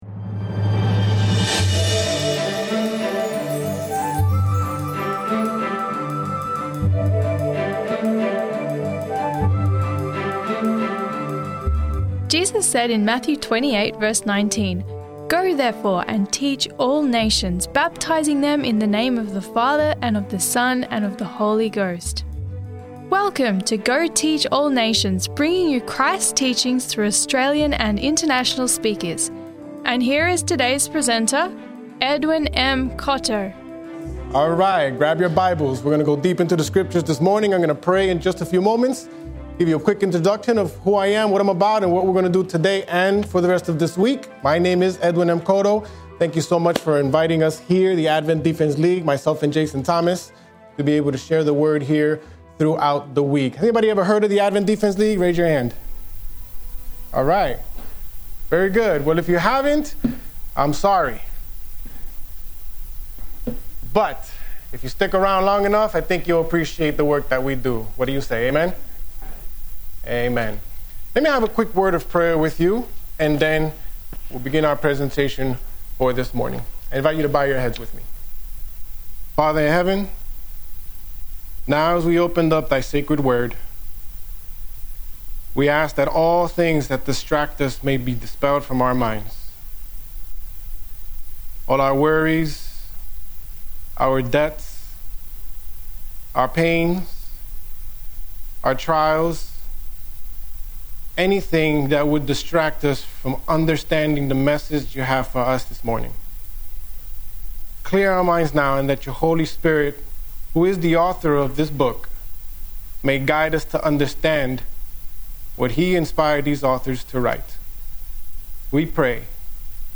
Sermon Audio: Go Teach All Nations
We are pleased to bring you Christ’s teachings through sermon of Australian and international speakers.